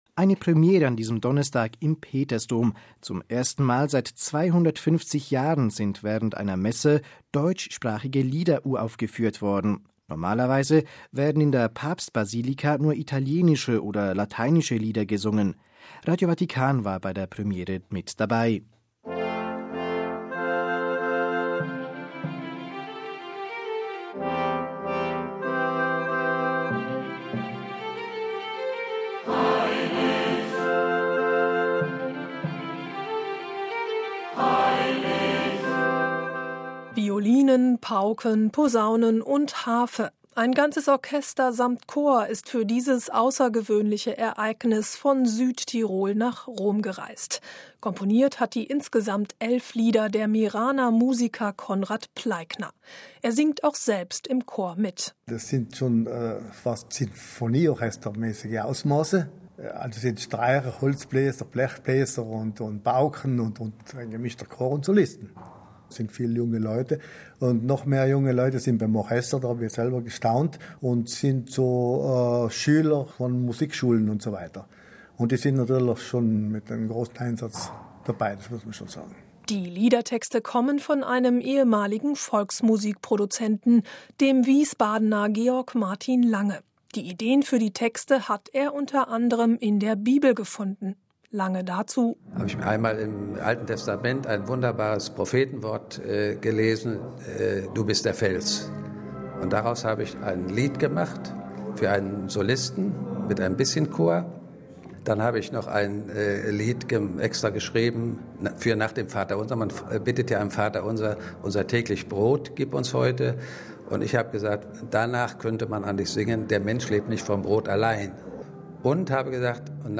Violinen, Pauken, Posaunen und Harfe. Ein ganzes Orchester samt Chor ist für dieses außergewöhnliche Ereignis von Südtirol nach Rom gereist.